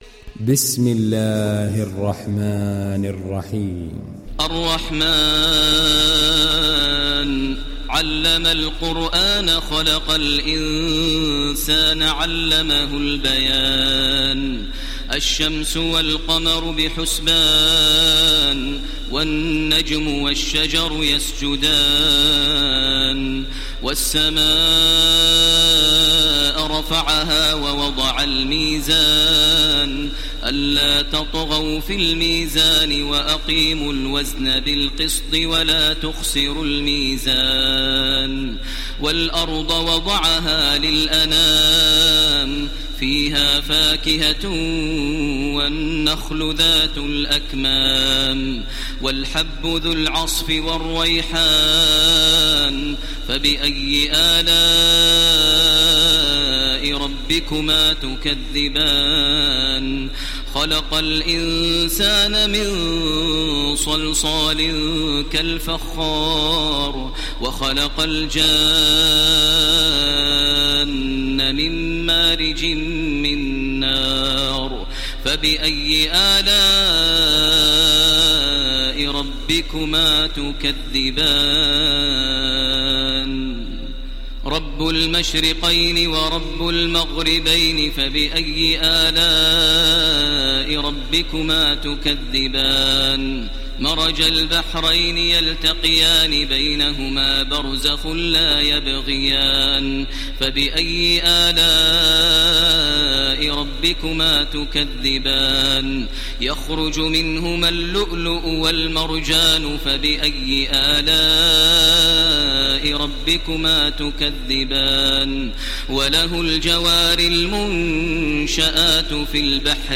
Download Surat Ar Rahman Taraweeh Makkah 1430